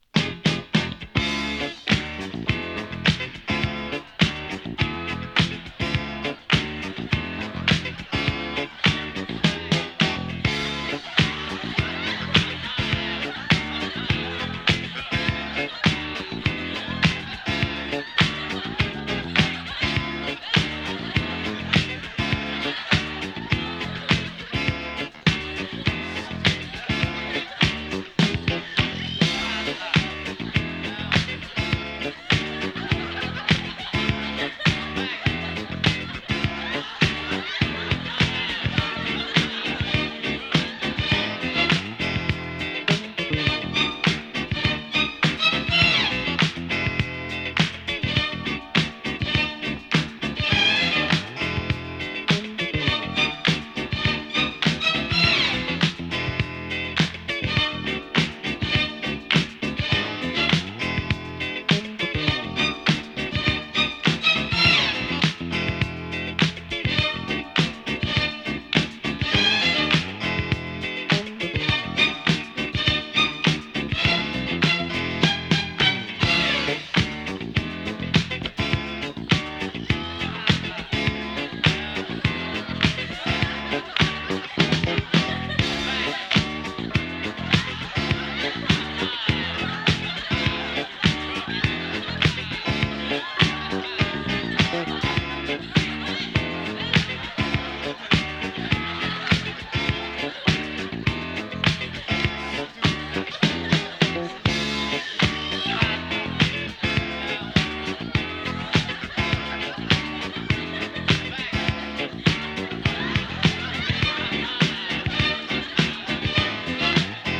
パーティーファンク